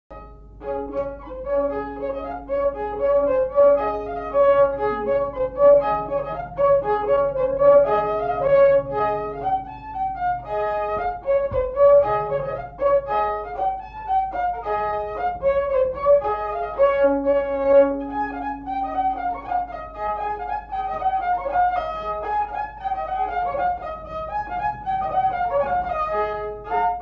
Mémoires et Patrimoines vivants - RaddO est une base de données d'archives iconographiques et sonores.
Chants brefs - A danser
branle : avant-deux
Pièce musicale inédite